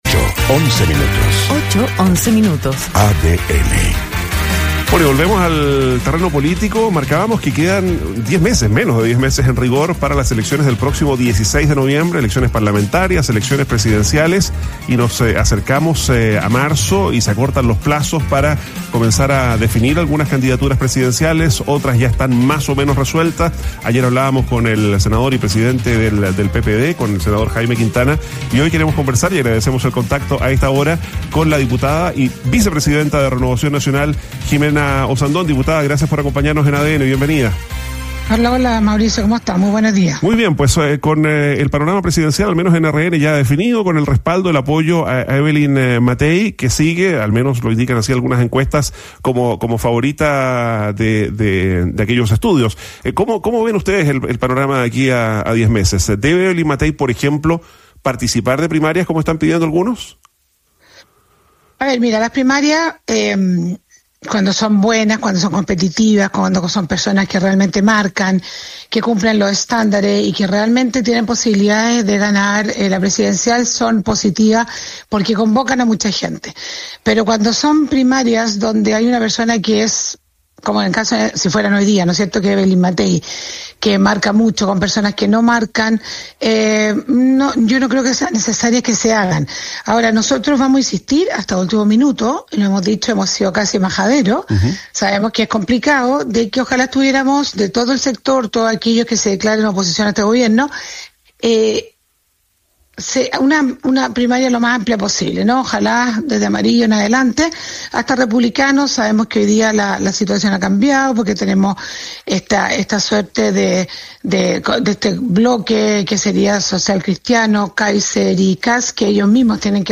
En una conversación con ADN Hoy, la vicepresidenta del partido ofreció su visión sobre el panorama presidencial a menos de diez meses de las elecciones del 16 de noviembre.